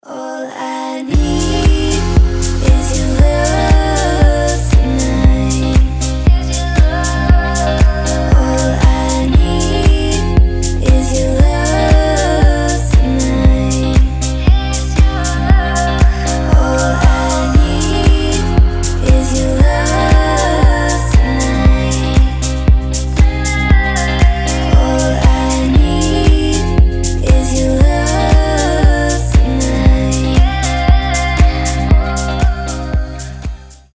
• Качество: 320, Stereo
deep house
женский голос
спокойные
chillout
релакс